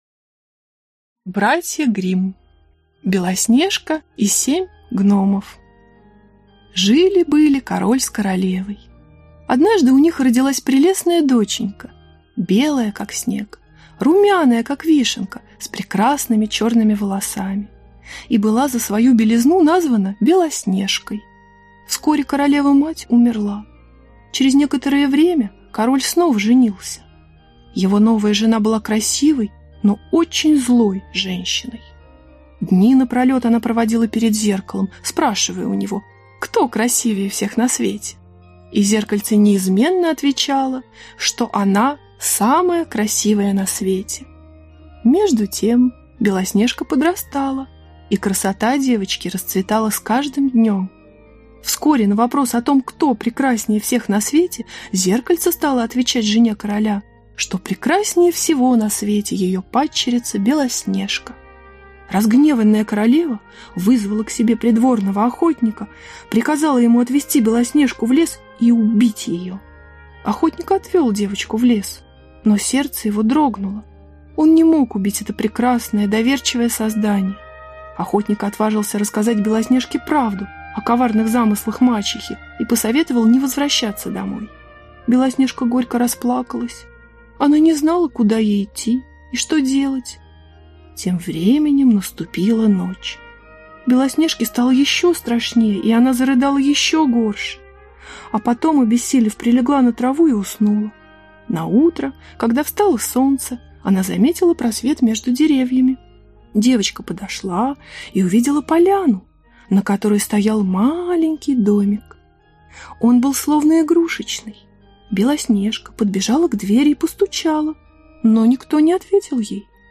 Аудиокнига Белоснежка и семь гномов | Библиотека аудиокниг